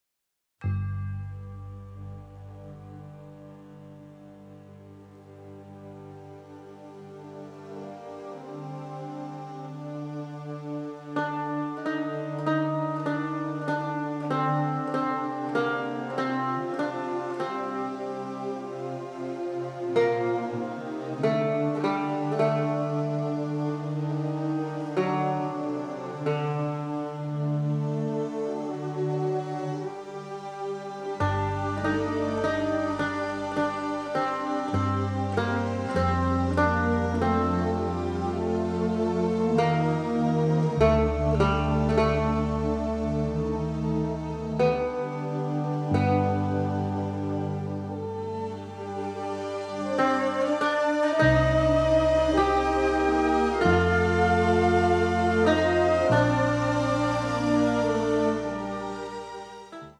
beautiful, nostalgic
radiantly passionate score